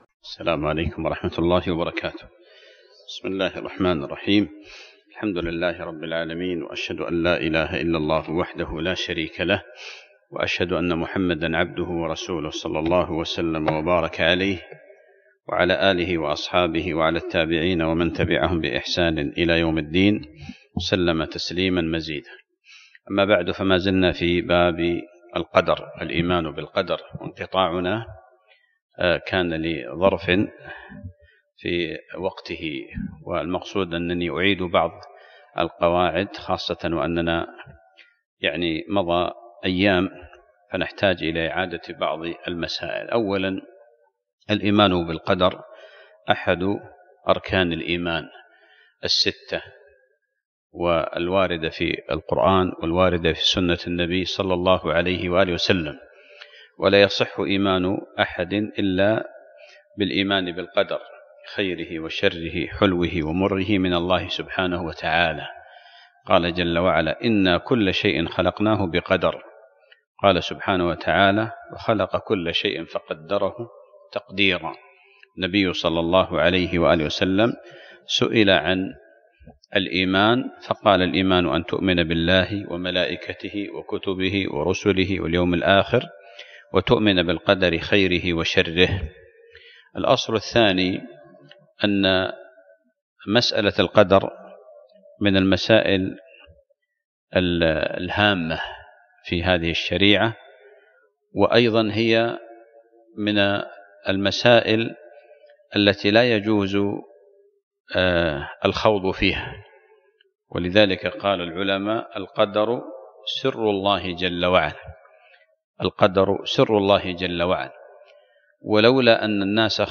الدرس السابع عشر